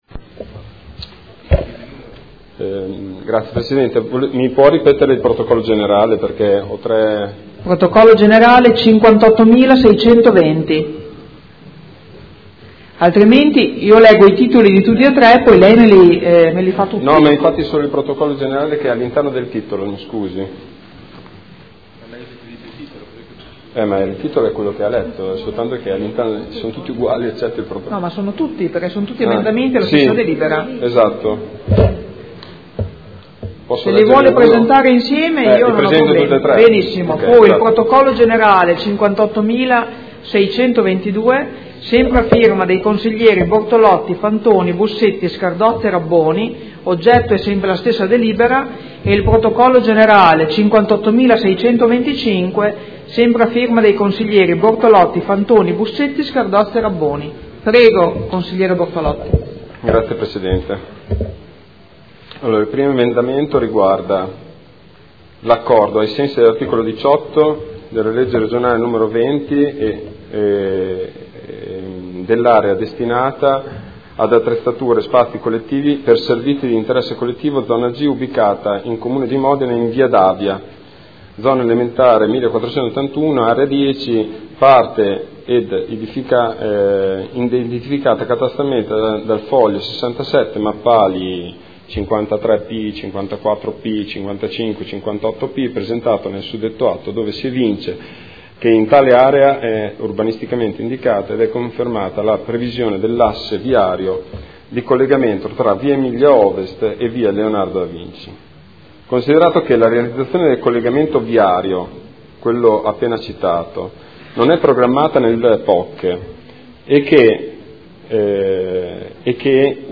Marco Bortolotti — Sito Audio Consiglio Comunale